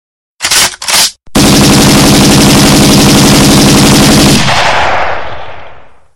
Light Machine Gun Sound Button: Meme Soundboard Unblocked
Light Machine Gun